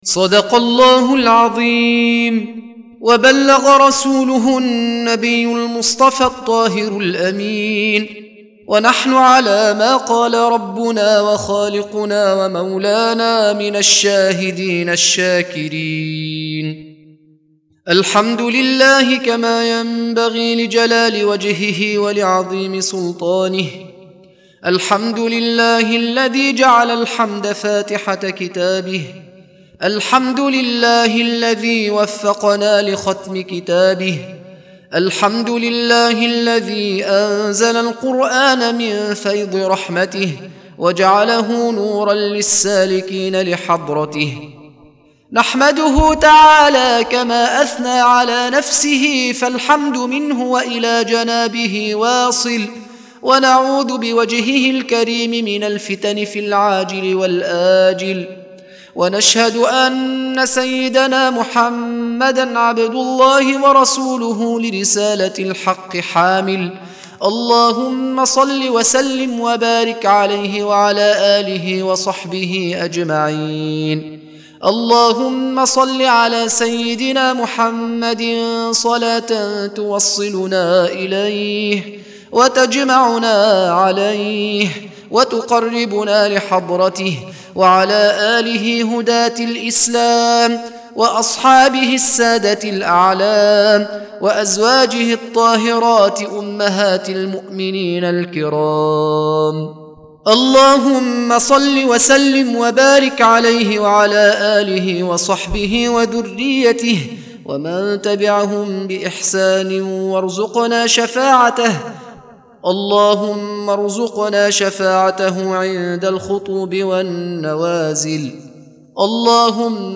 دعاء ختم القرآن